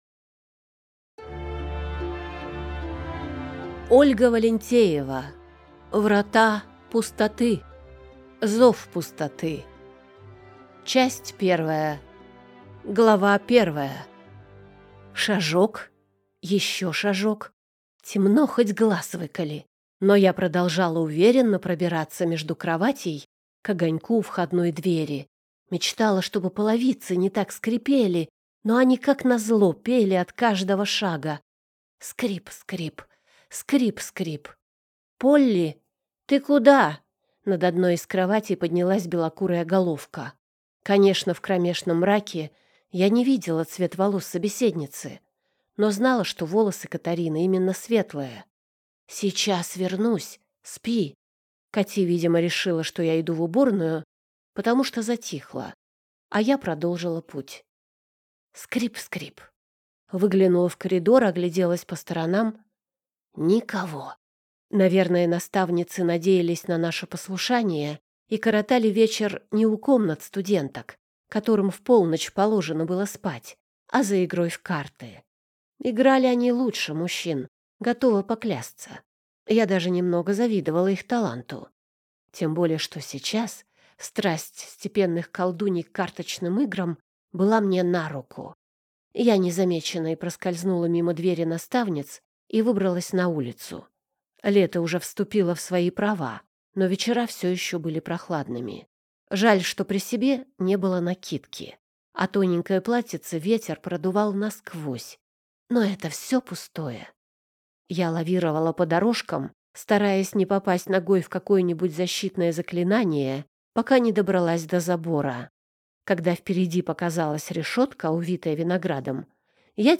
Аудиокнига Врата пустоты. Зов пустоты | Библиотека аудиокниг